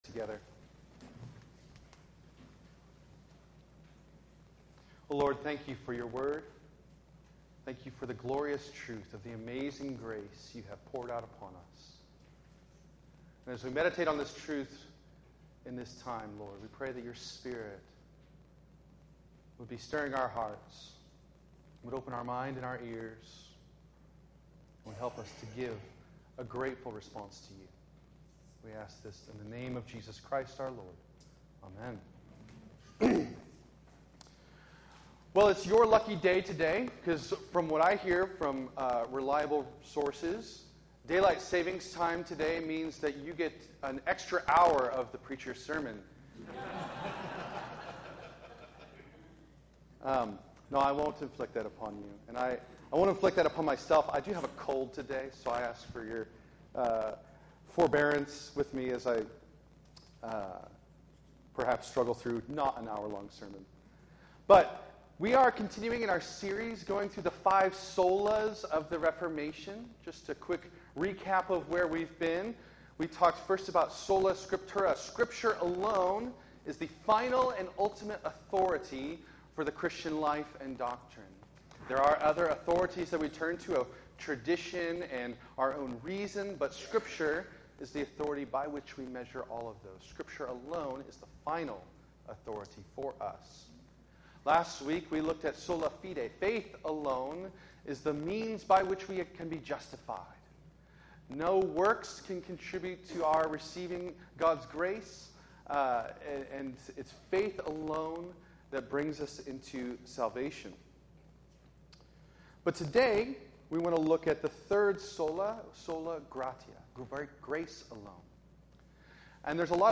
In this sermon continuing our series going through the Five Solas of the Reformation